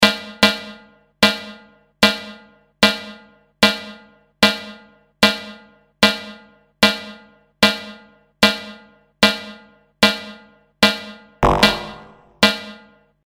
Clanging.wav